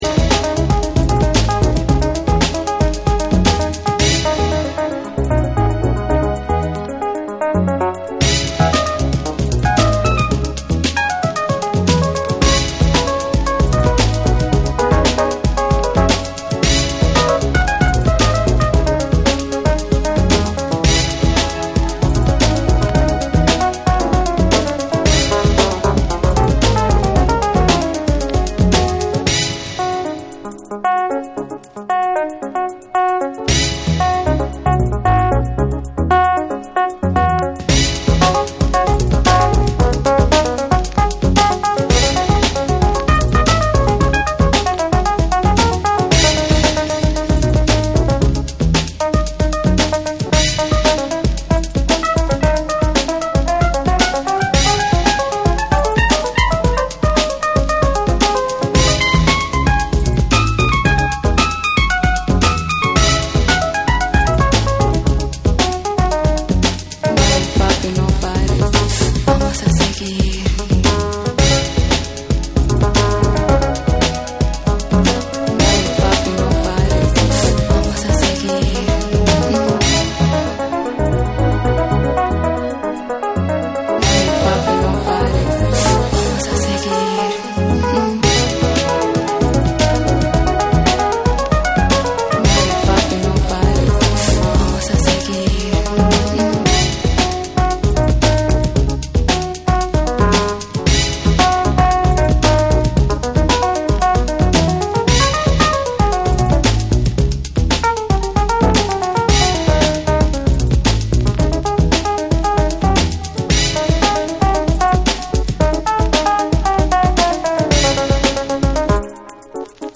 リズミカルで軽やかに奏でるエレピのメロディーが印象的な
パーカッシヴでグルヴィーなベースラインが牽引した
※盤面にスリキズ、チリノイズ入る箇所あり。
TECHNO & HOUSE / BACK TO BASIC